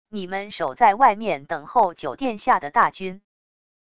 你们守在外面等候九殿下的大军wav音频